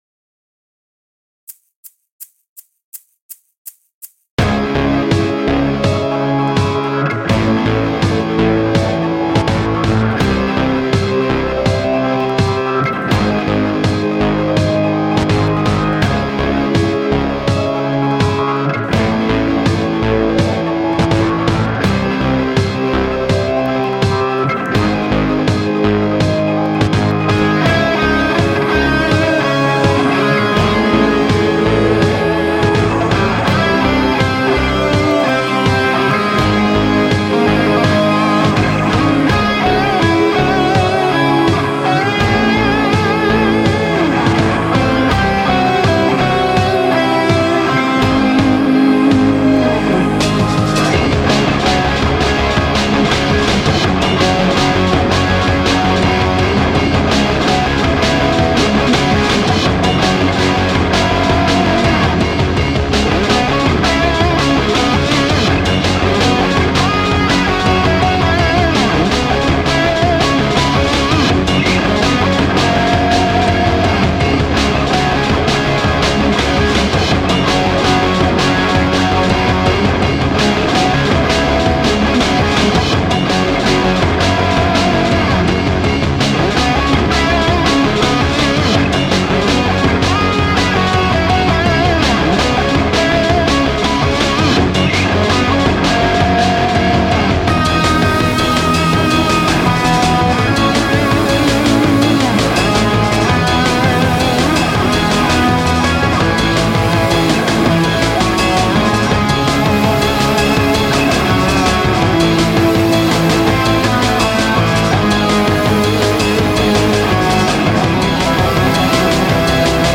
Jungle lab avec les guitares